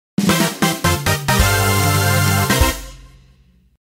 The sound that plays when finishing a race below 1st place